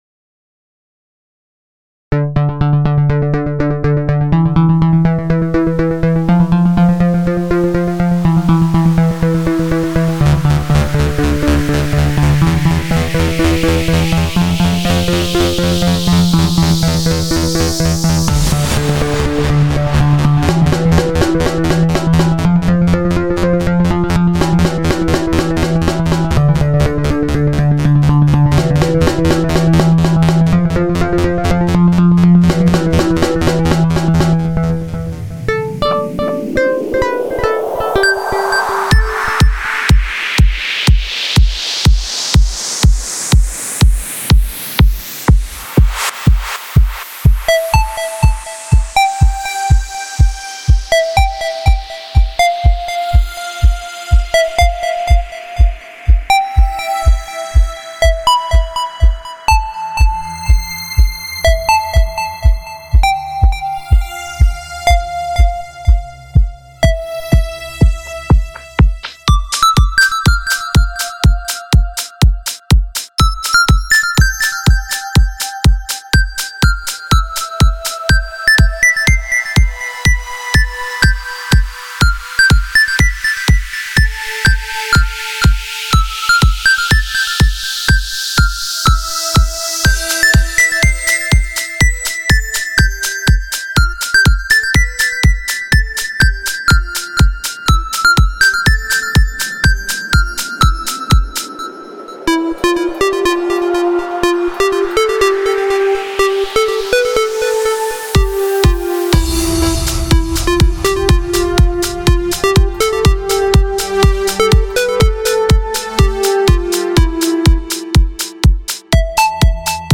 آهنگهای پاپ فارسی
بی کلام